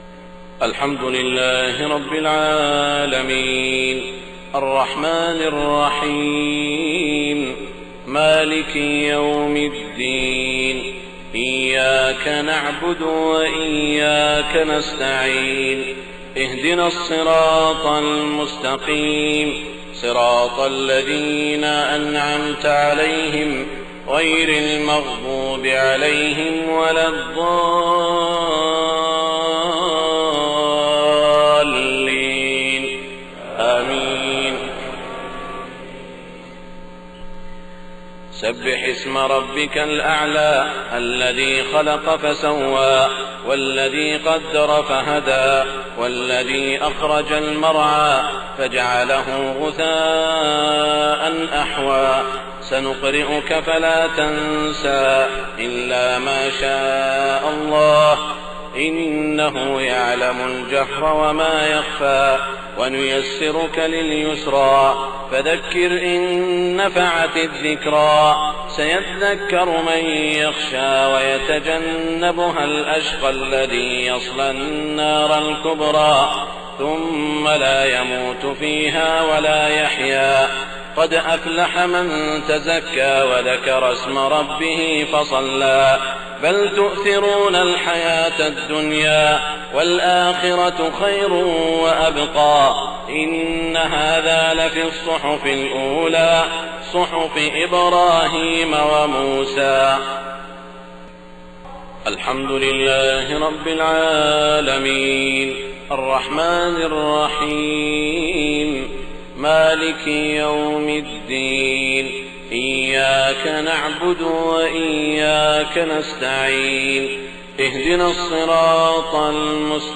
صلاة الجمعة 5 رمضان 1429هـ سورتي الأعلى و الغاشية > 1429 🕋 > الفروض - تلاوات الحرمين